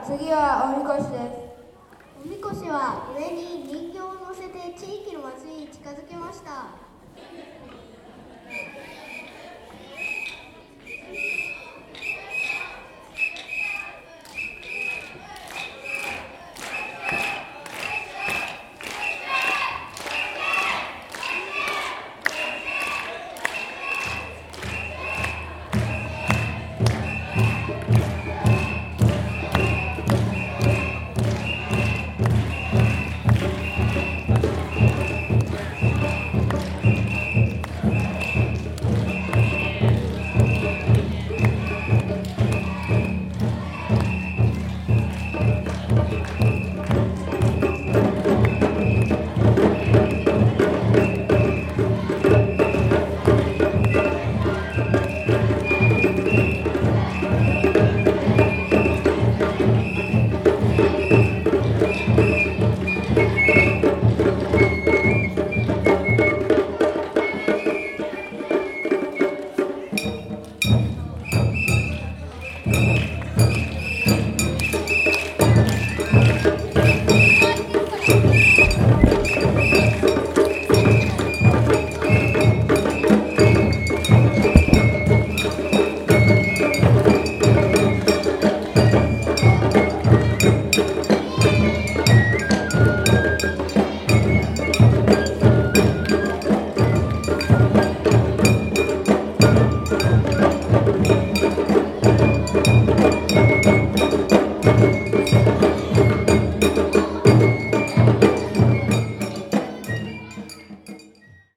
お神輿。